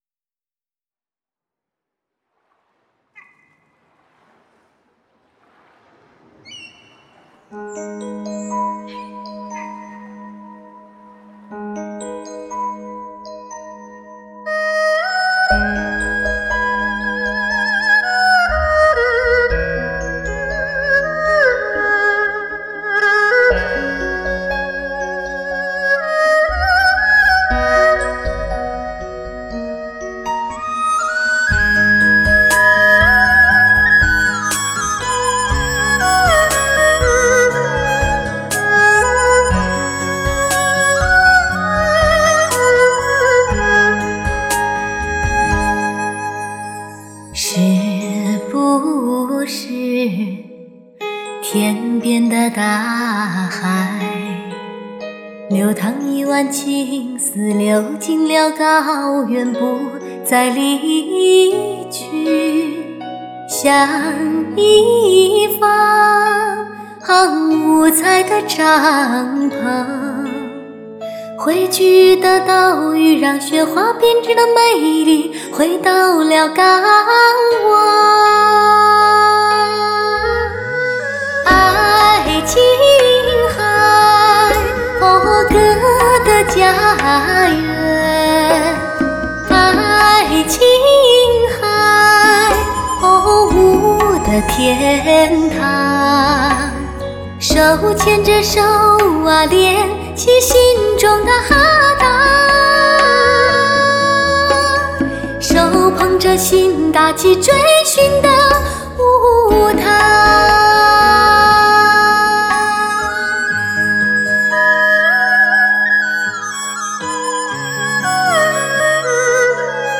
Жанр: Modern Traditional / Chinese pop